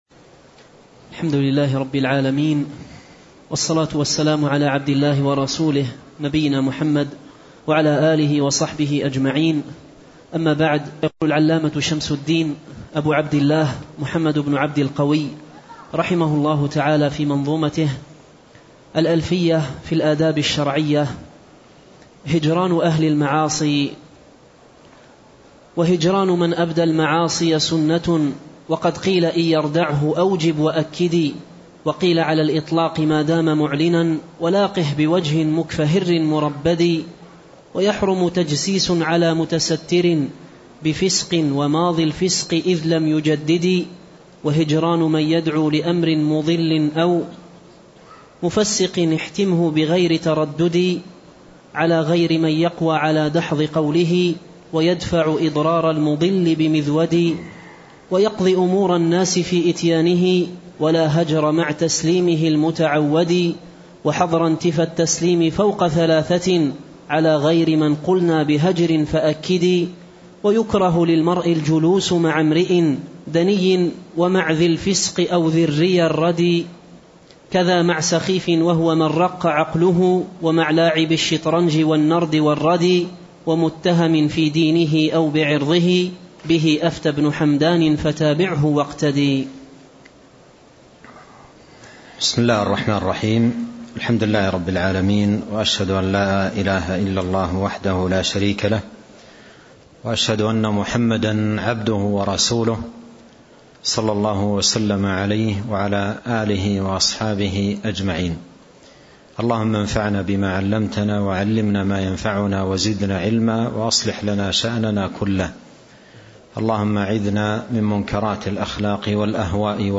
شرح الألفية في الآداب الشرعية الدرس 6